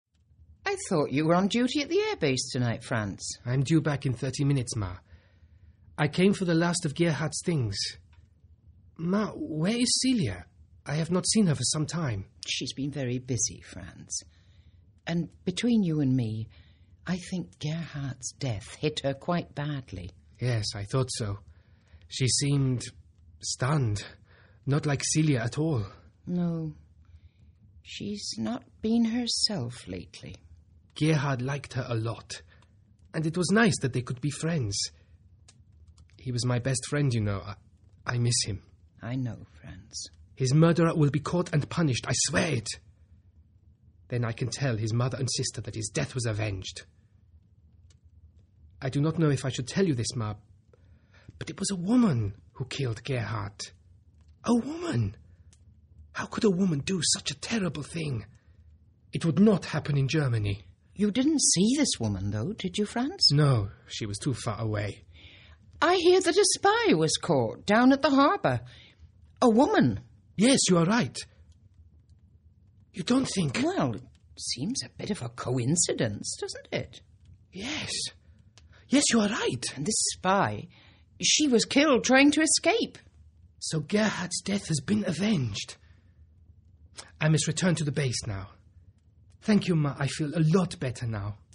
Drama
(Private Hutter/Hartung/English Lieutenant)